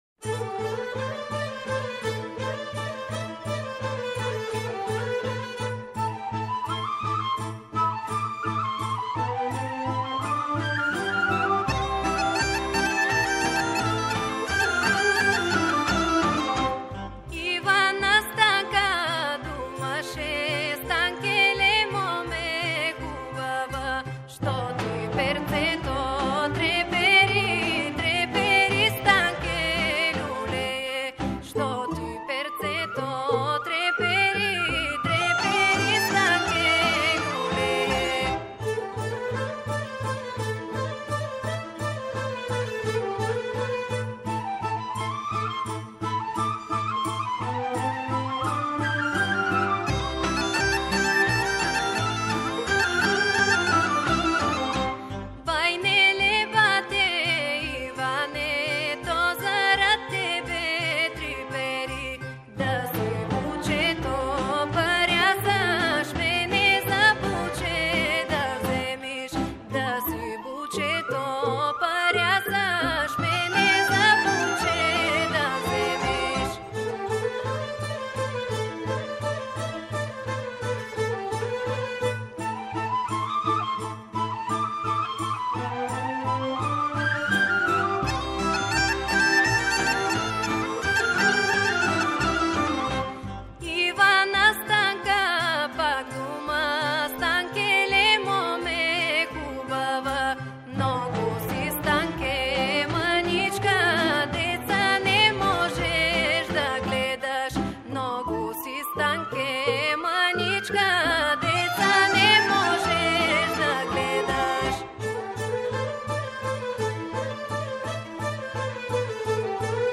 Млада народна певачица
народне песме са различитих музичких подручја Бугарске